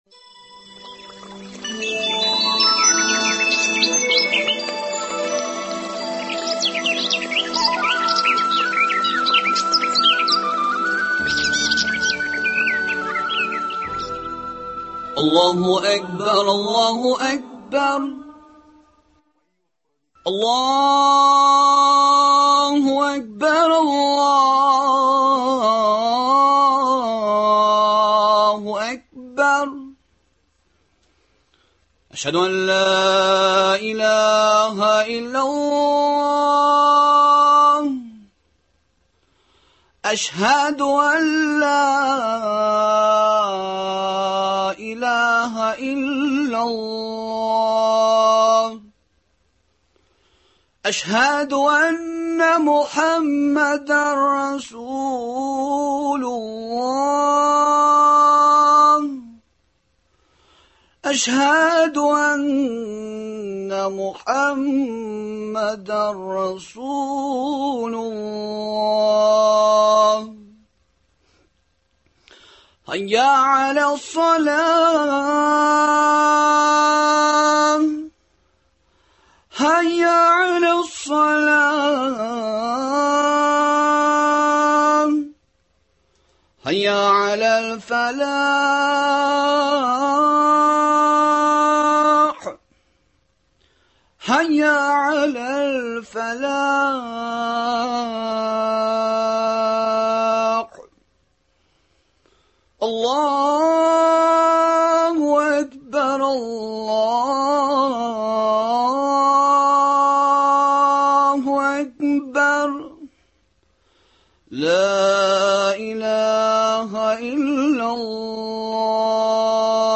әңгәмәдән белә аласыз.